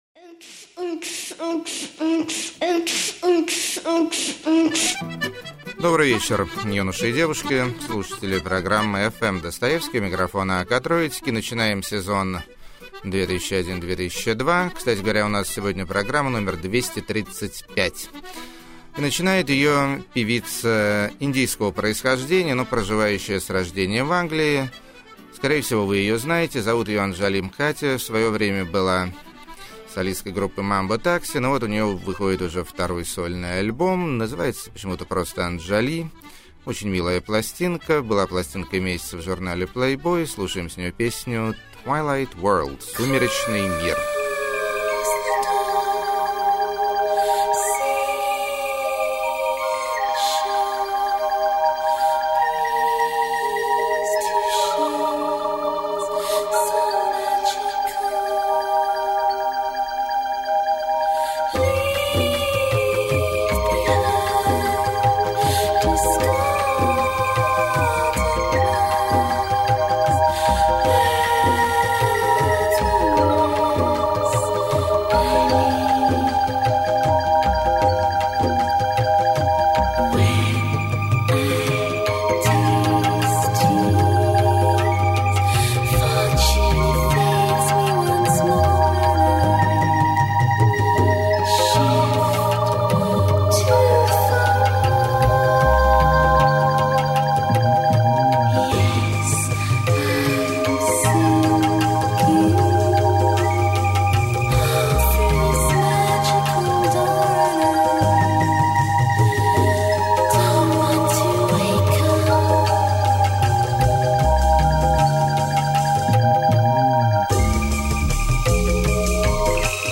Танго Нуар.
Странноватое Мутант-диско.
Забавный Сэмпло-фанк.
Ливерпульский Кантри-surf.
Сумасшедший Голос.] 13.
Кельтский Транс.]